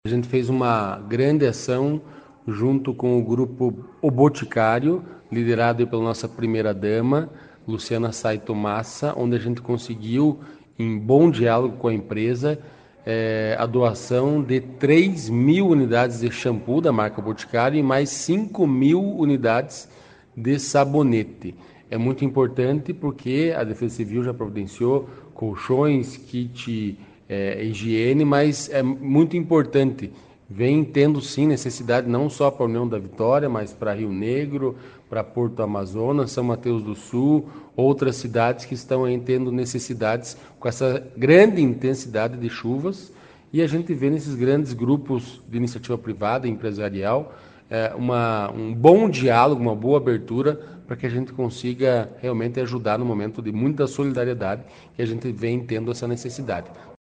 Sonora do secretário da Justiça e Cidadania, Santin Roveda, sobre o o início da campanha de arrecadação de itens para famílias atingidas pelas enchentes dos últimos dias